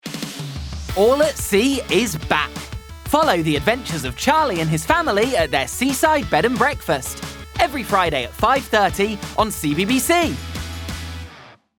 CBBC - Presenter, Energetic, Upbeat